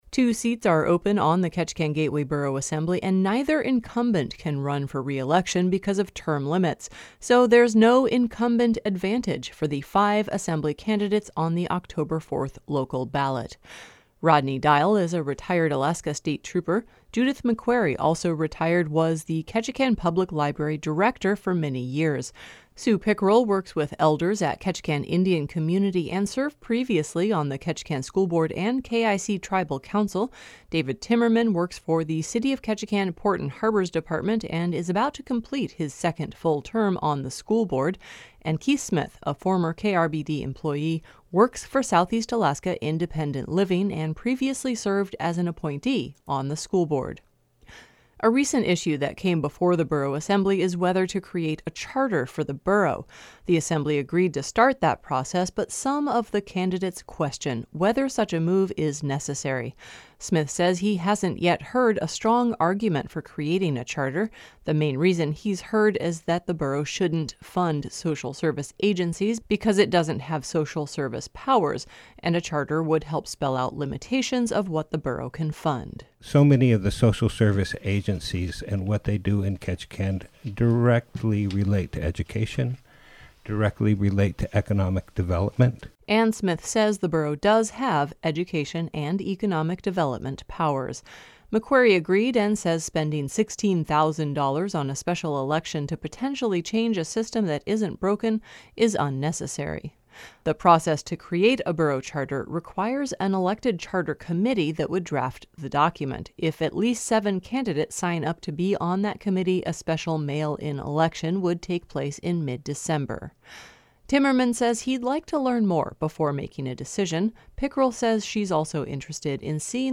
Here is a summary from that event.